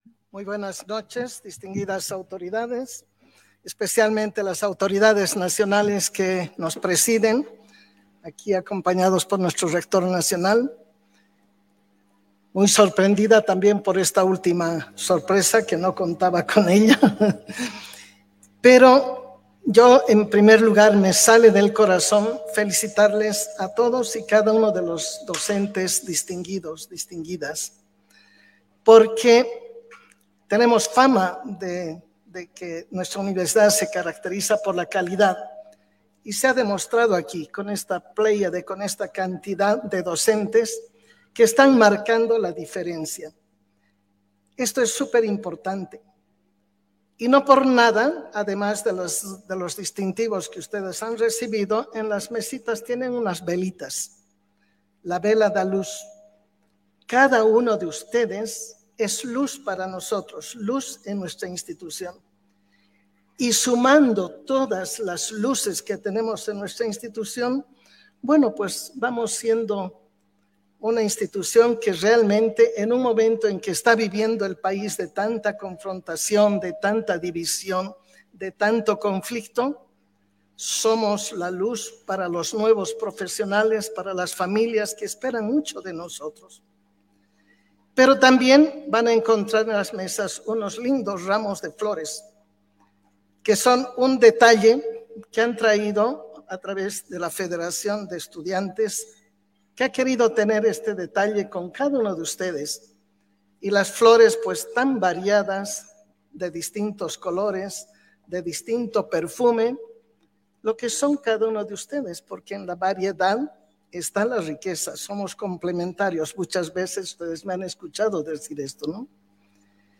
RN 07.06.2023. El 6 de junio de 2023 se realizó en Sede Cochabamba UCB el homenaje por el Día del Maestro, reconociendo la labor educativa de los docentes destacados de esta casa superior de estudios.